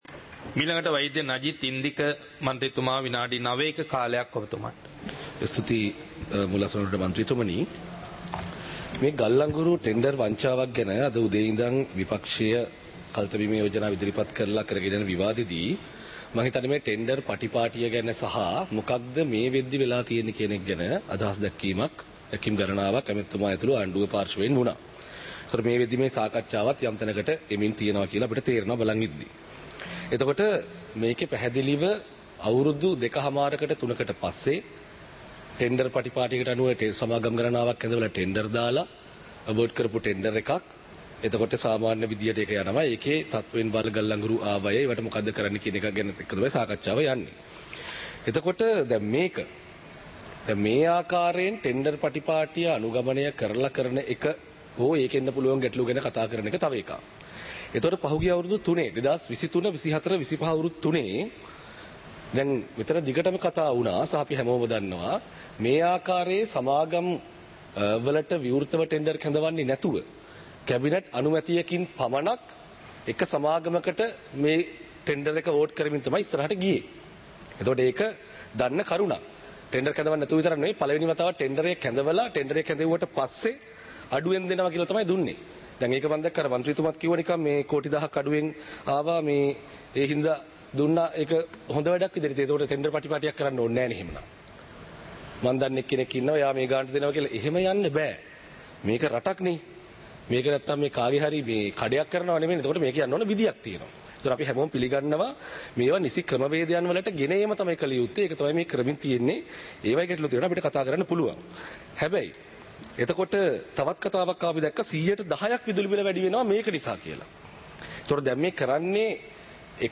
සභාවේ වැඩ කටයුතු (2026-02-20)
පාර්ලිමේන්තුව සජීවීව - පටිගත කළ